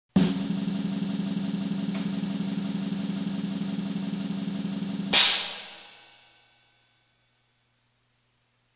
Download Drum Roll sound effect for free.
Drum Roll